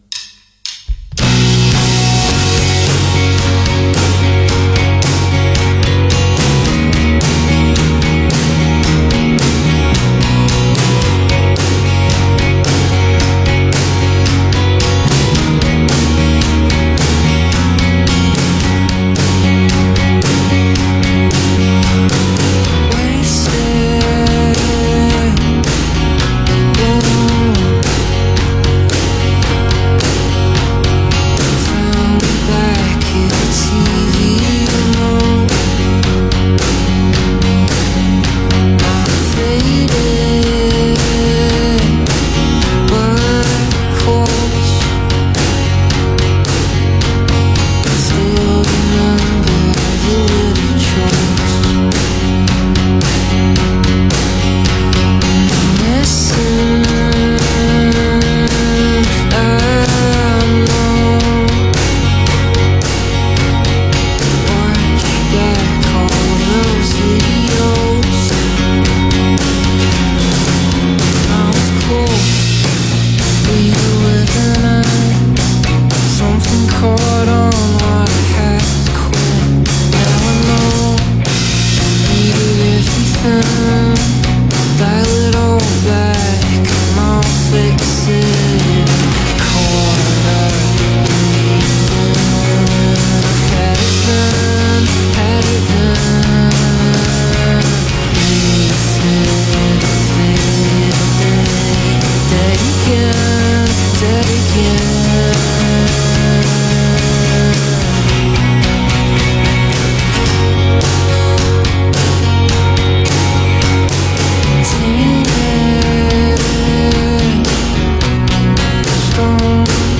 tender finger picks
distinctive half-whisper